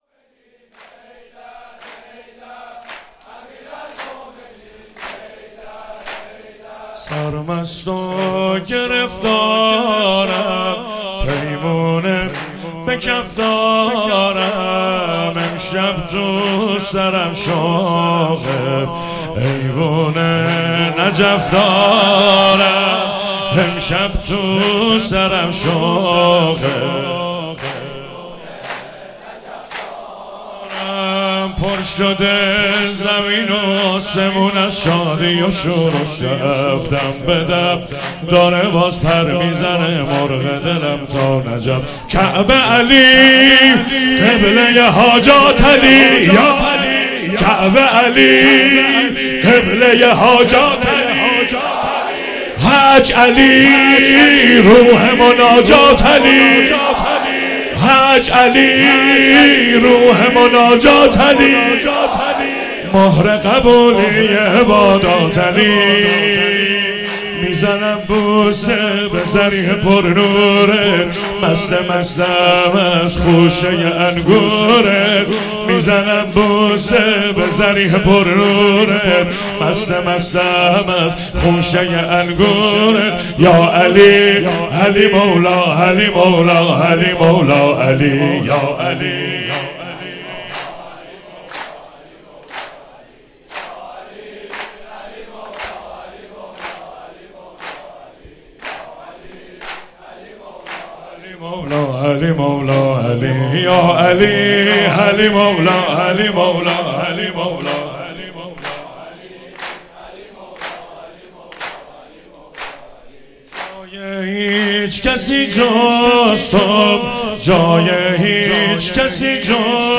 مراسم عید غدیر 16 شهریور 96
چهاراه شهید شیرودی حسینیه حضرت زینب (سلام الله علیها)
سرود